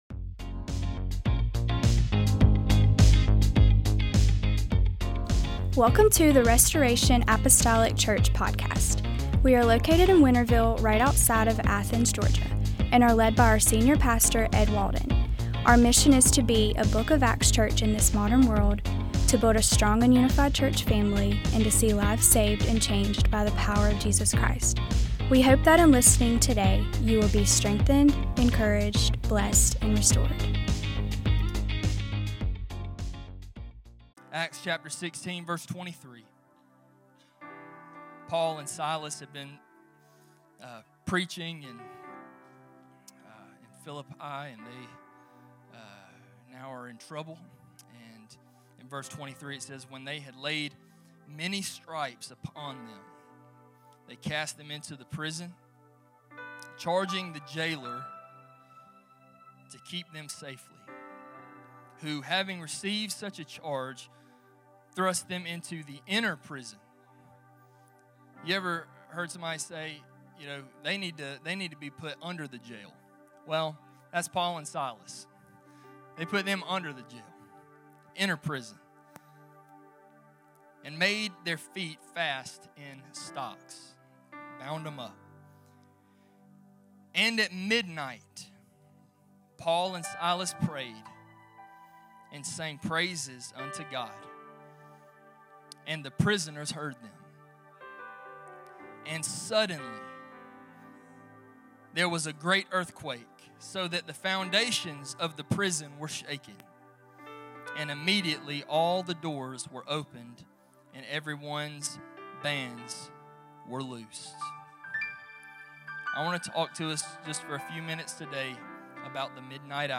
Restoration Apostolic Church The Midnight Hour Jun 29 2025 | 00:26:36 1x 00:00 / 00:26:36 Subscribe Share Apple Podcasts Spotify Overcast RSS Feed Share Link Embed